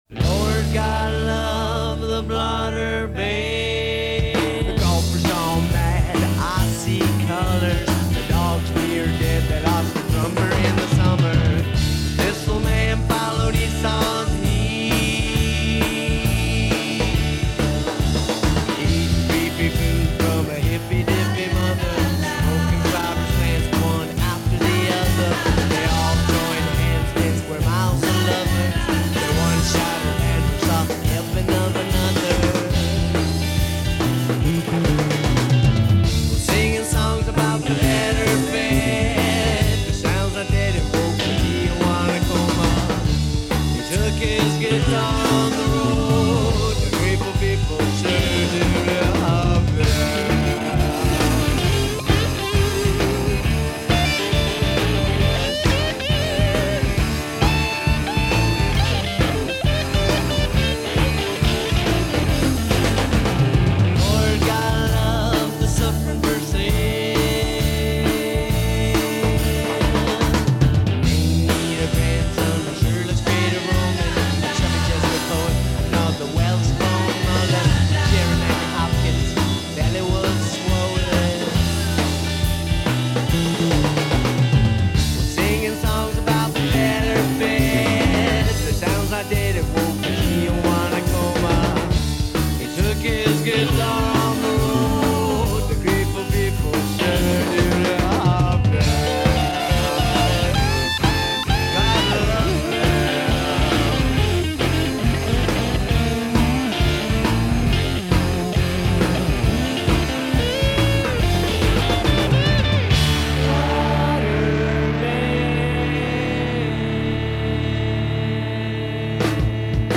Some of the sound quality is pretty rough.